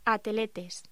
Locución: Ateletes